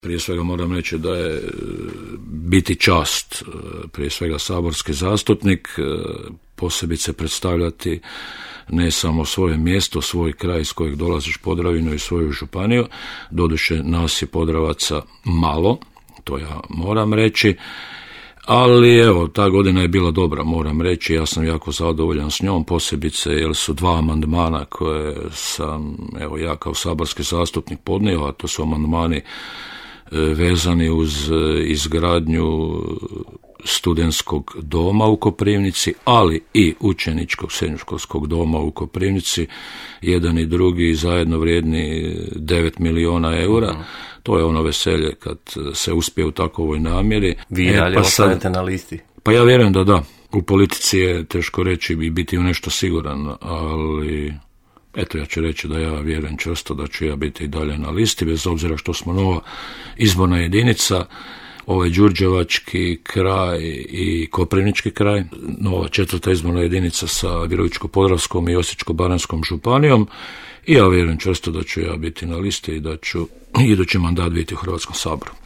Darko Sobota, osim što je načelnik općine Kalinovac, obnaša i dužnost saborskog zastupnika.